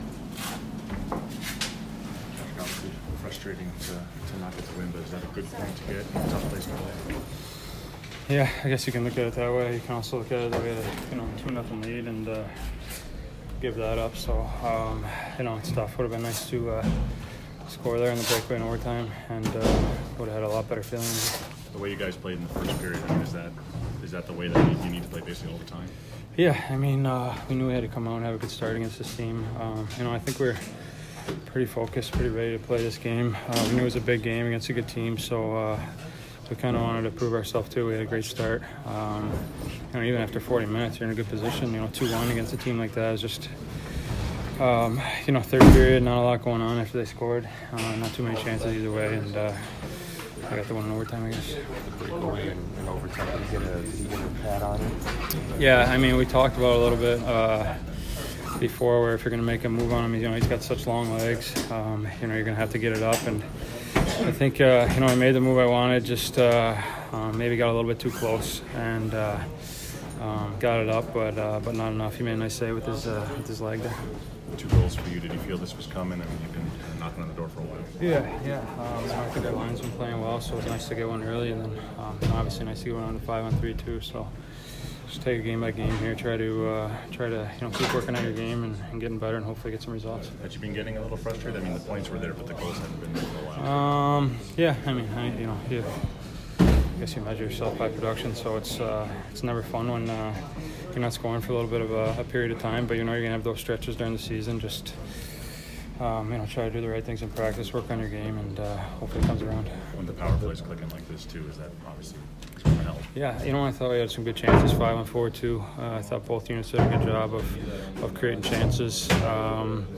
Patrick Kane Post-Game 11/22